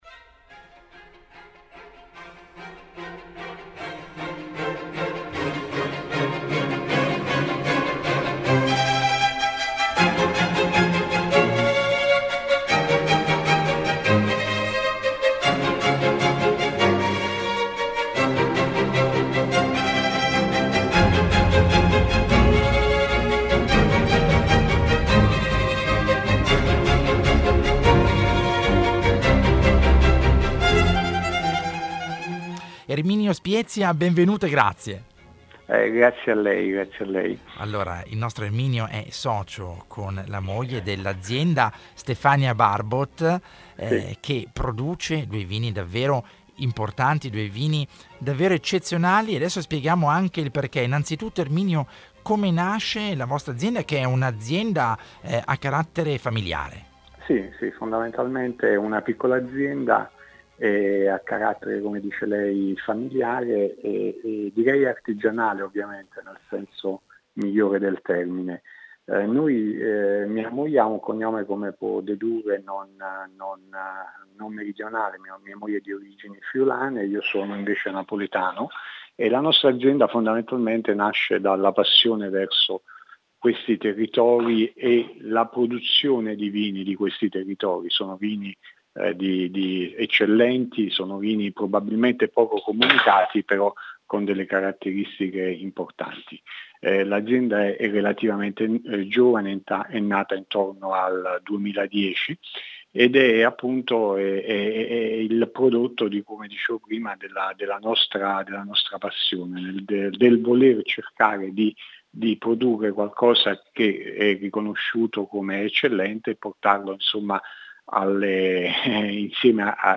INTERVISTA CON